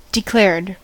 declared: Wikimedia Commons US English Pronunciations
En-us-declared.WAV